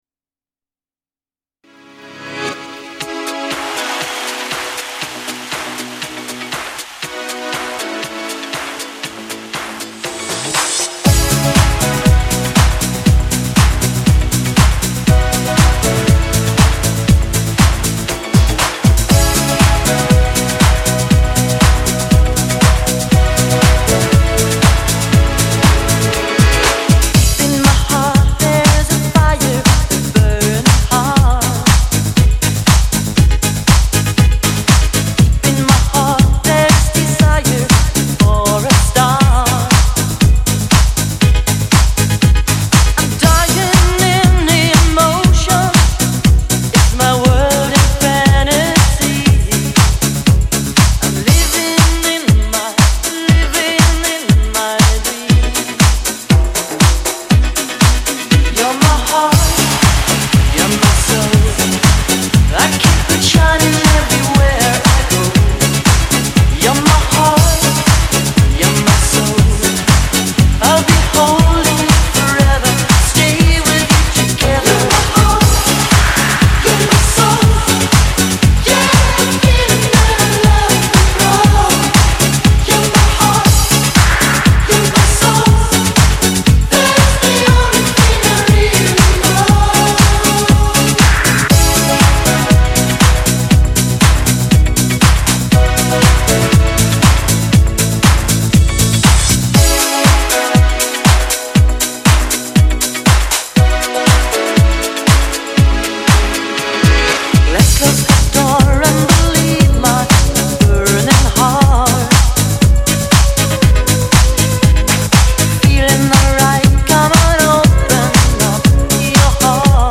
New Remix